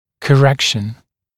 [kə’rekʃn][кэ’рэкшн]коррекция, исправление